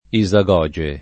isagoge [ i @ a g0J e ] s. f.